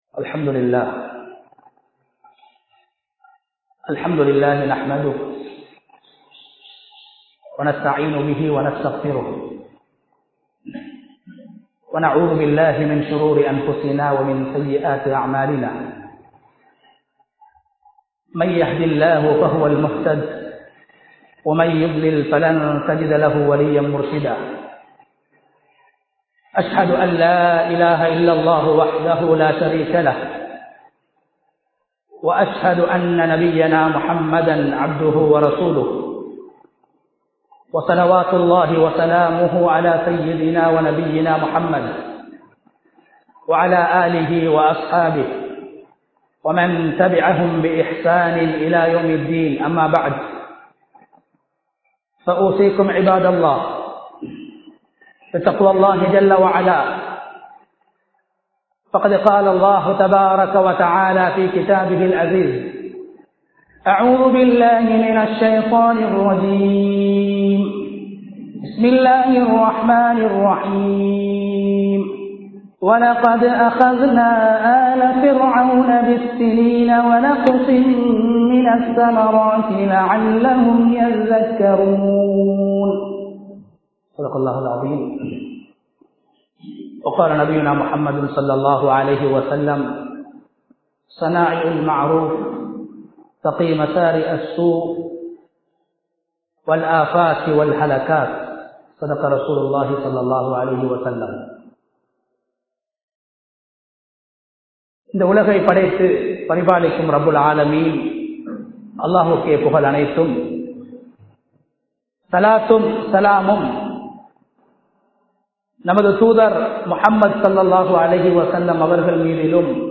உணவு நெருக்கடியா? | Audio Bayans | All Ceylon Muslim Youth Community | Addalaichenai
Kurunegala, Hettipola Jumua Masjith 2022-04-15 Tamil Download